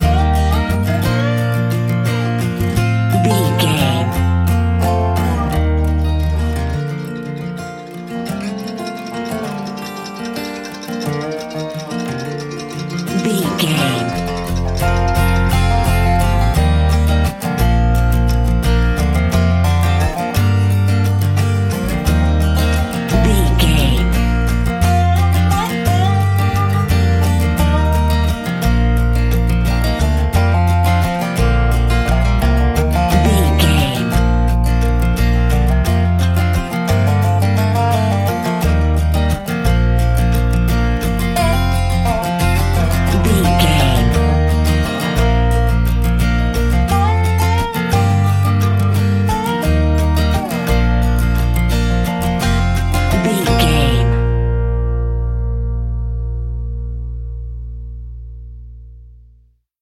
Ionian/Major
B♭
acoustic guitar
bass guitar
banjo